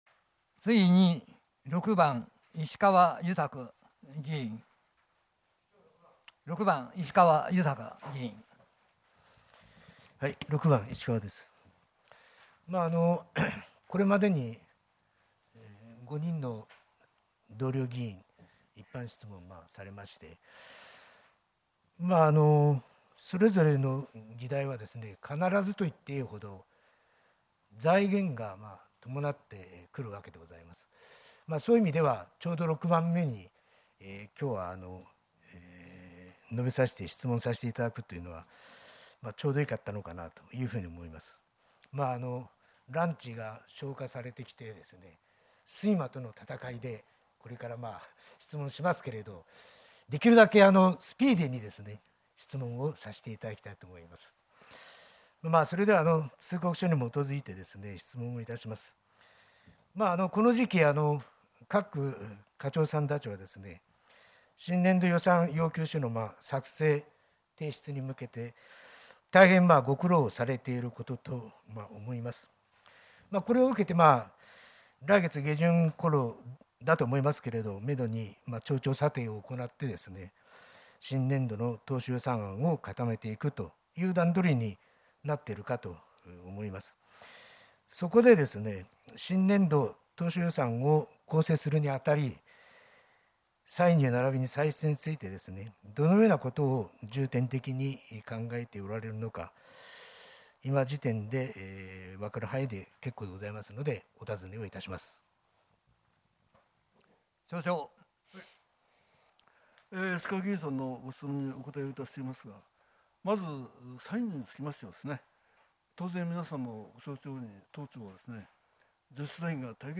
令和4年12月定例会 2日目（一般質問） | 出雲崎町ホームページ